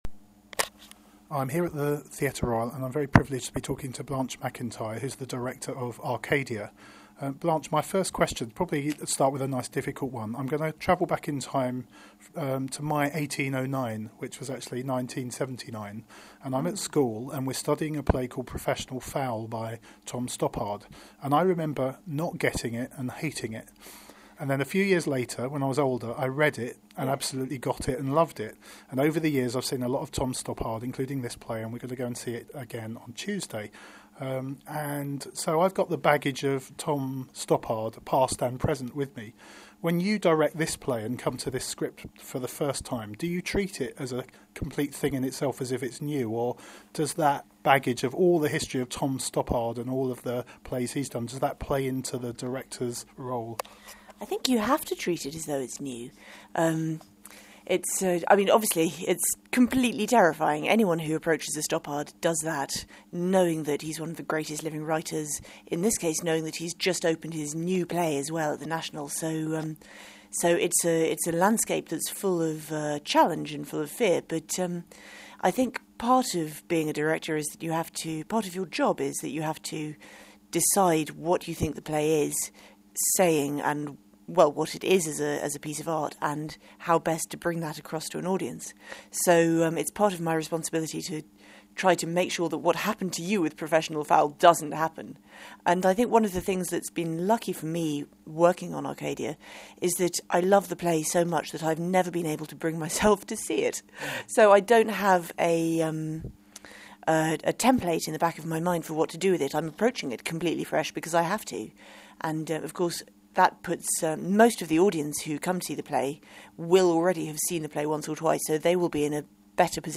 Arcadia first premiered at the National Theatre and comes to the Theatre Royal Brighton . Listen to our interview